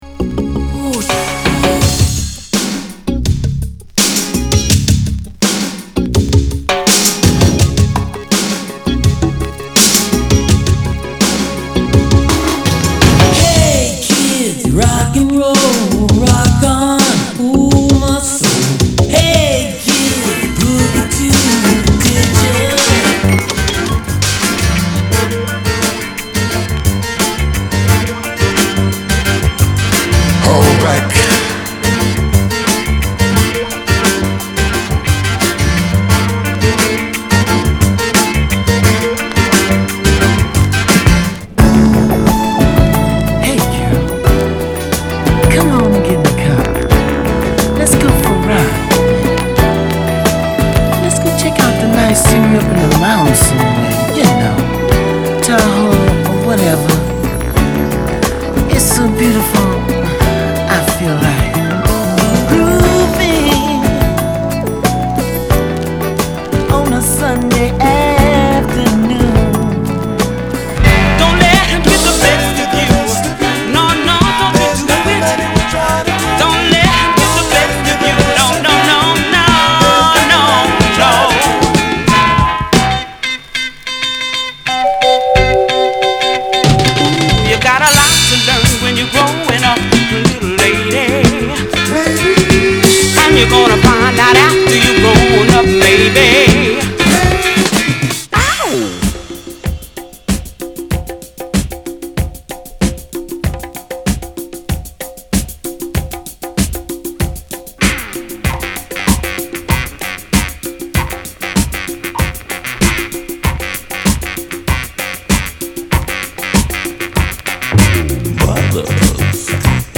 category R&B & Soul